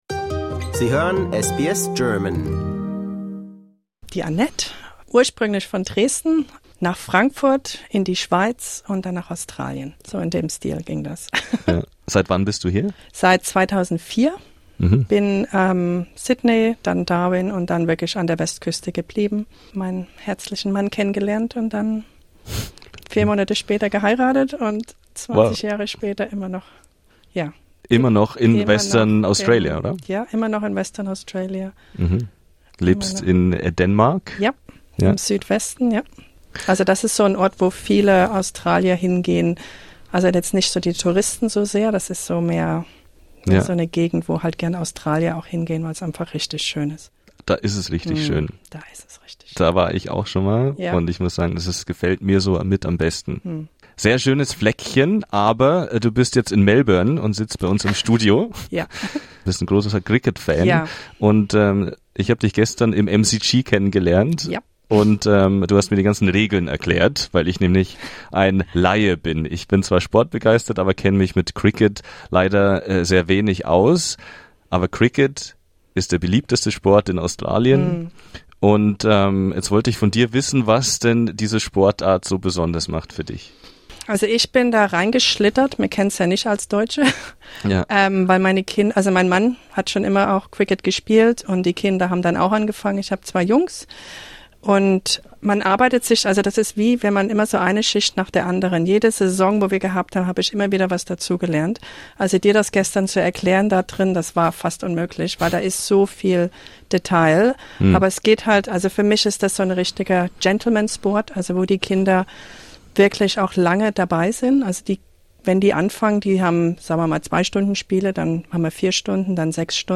Zusammen mit ihrer Familie ist sie extra nach Melbourne gereist, um im Stadion live mit dabei zu sein. Im Interview teilt sie Tipps für Sportbegeisterte, die mehr über die Sportart lernen möchten und verrät, warum sie sich ins Cricket verliebt hat.